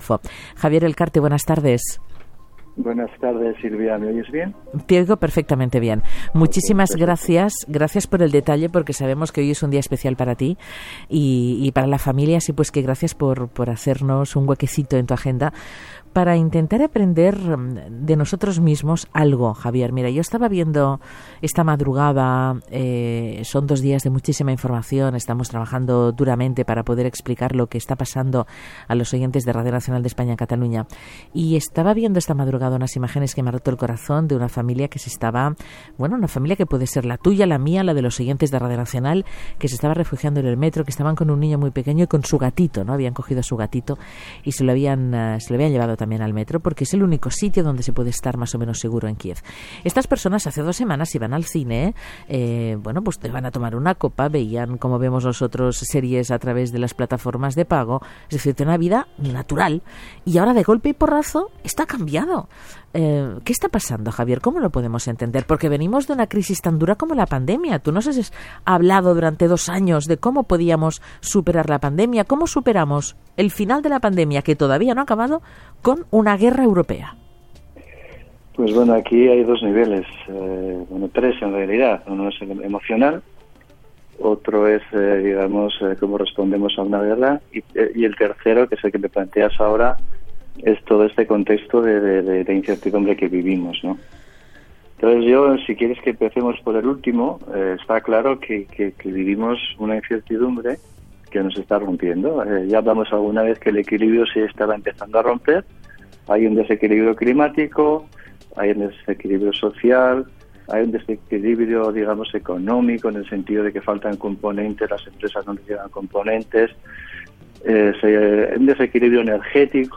Escucha la entrevista completa en castellano, pinchando en el siguiente enlace: